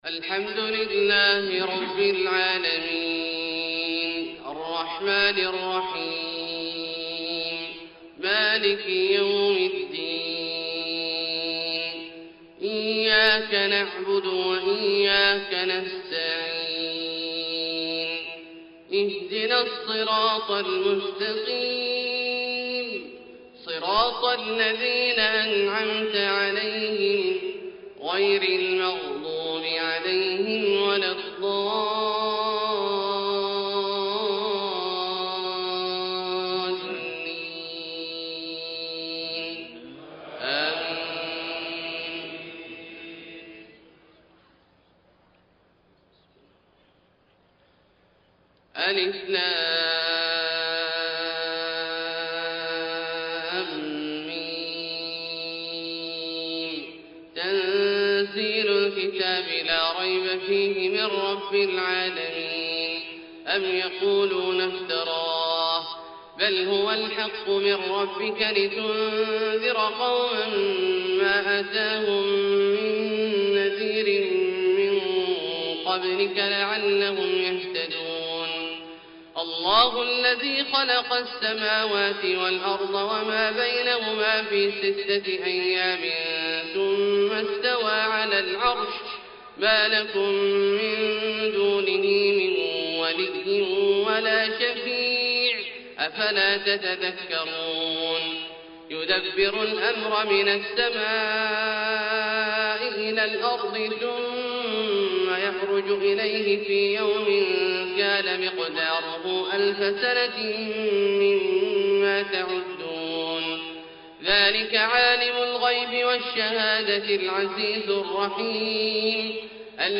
فجر 9-4-1433هـ سورتي السجدة والإنسان > ١٤٣٣ هـ > الفروض - تلاوات عبدالله الجهني